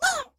pain2.ogg